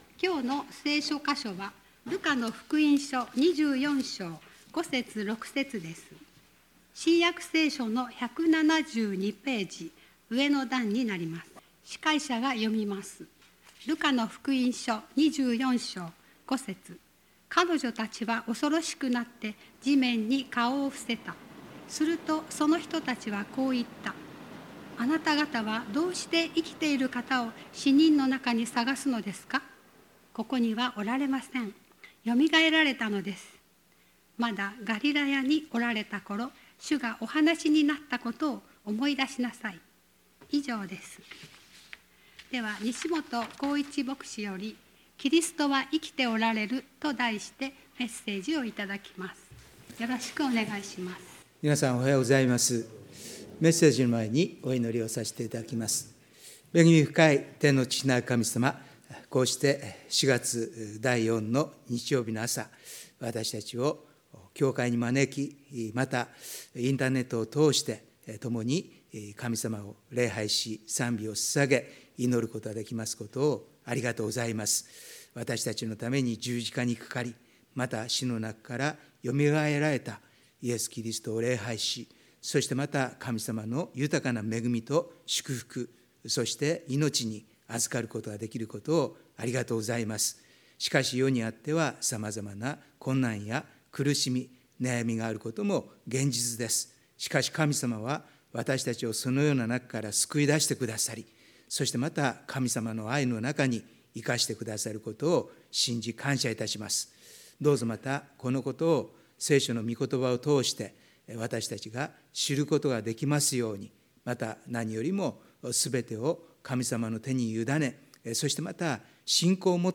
2023年 4/23 第四主日 伝道礼拝